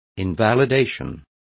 Complete with pronunciation of the translation of invalidation.